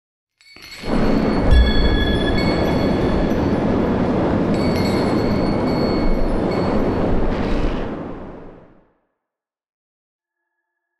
abjuration-magic-sign-rune-complete.ogg